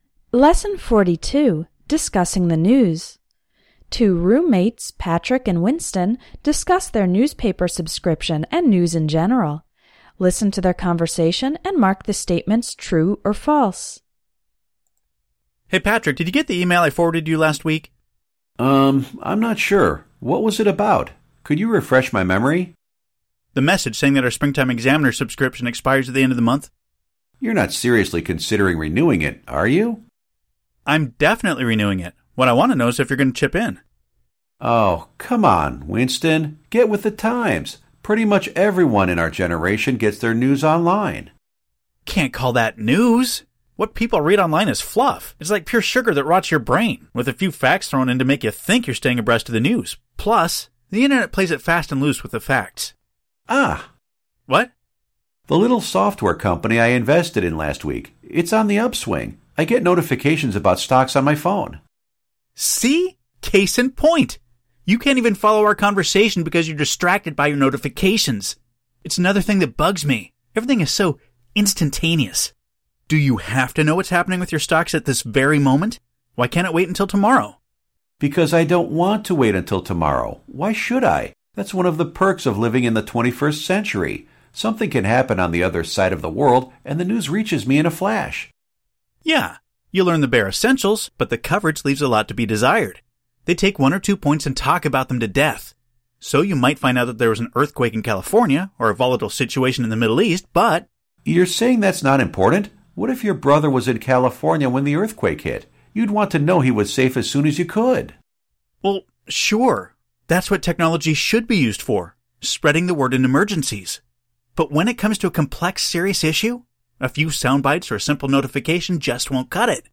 Everyday English Conversation: Discussing the News